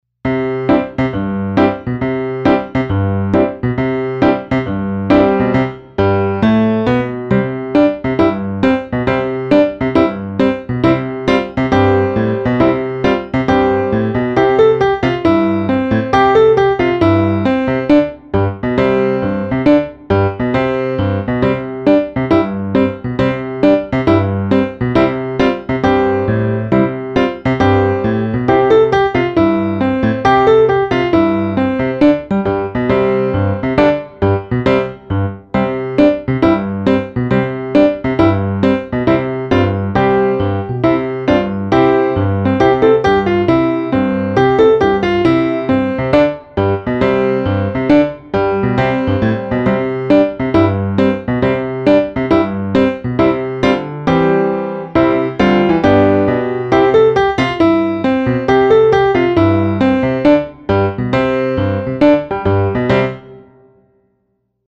podkład do powitań poprzedzających zabawę. przypominam tekst: